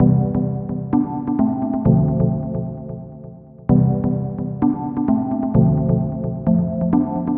音乐盒
描述：只是一个奇怪的音乐盒。
标签： 130 bpm RnB Loops Bells Loops 1.24 MB wav Key : D FL Studio
声道立体声